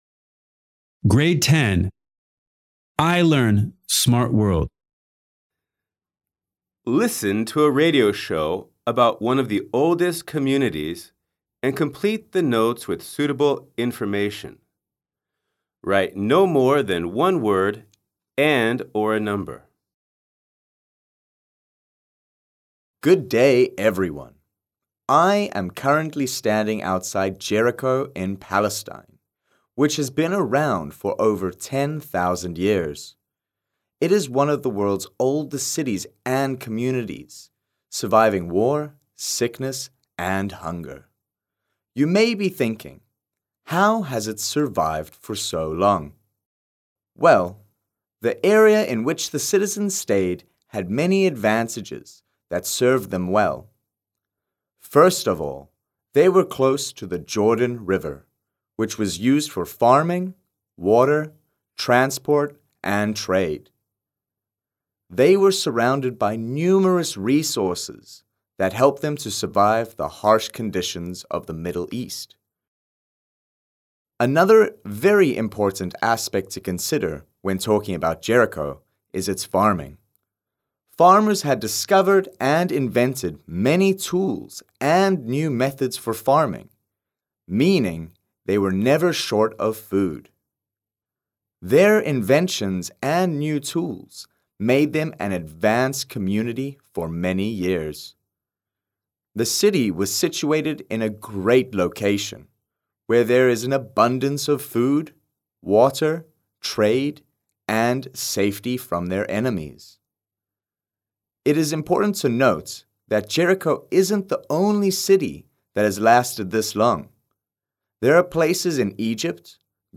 Listen to a radio show about one of the oldest communities and complete the notes with suitable information.